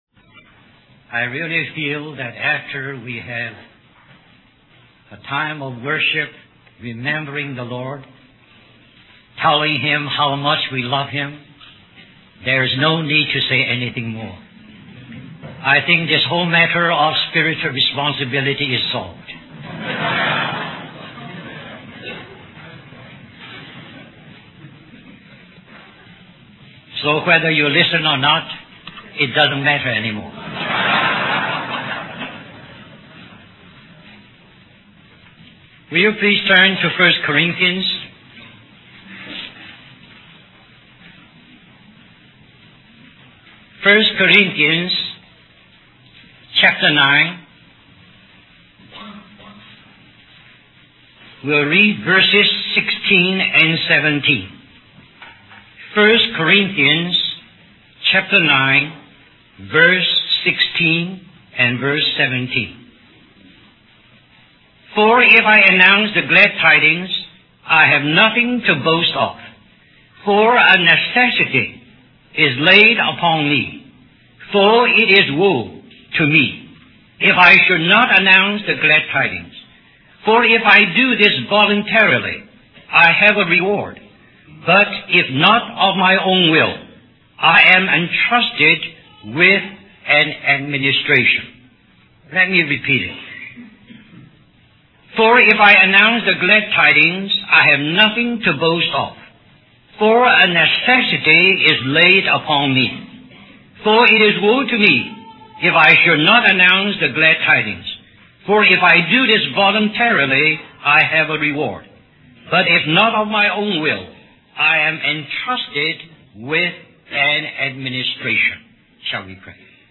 A collection of Christ focused messages published by the Christian Testimony Ministry in Richmond, VA.
1989 Harvey Cedars Conference Stream or download mp3 Summary This message is also printed in booklet form under the title